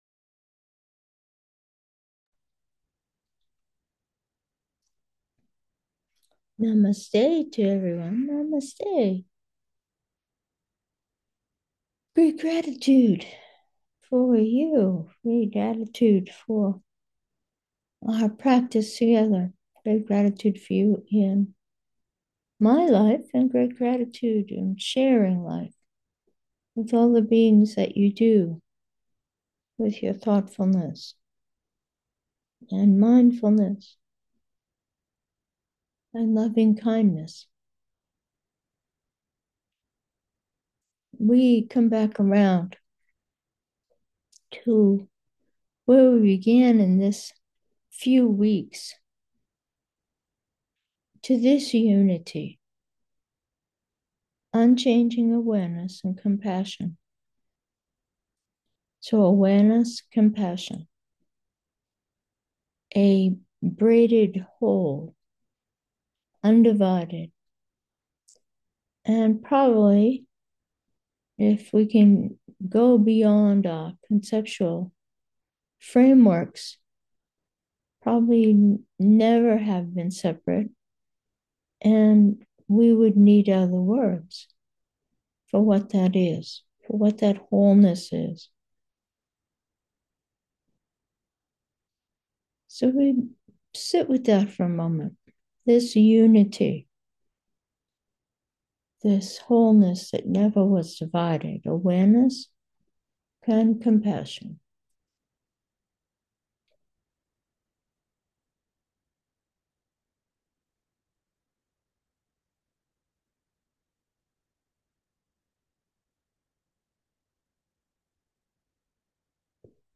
Meditation: original unity